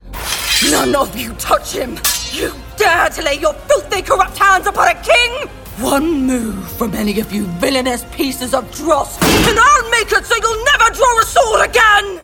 Young Adult, Adult, Teenager, Child, Mature Adult
Has Own Studio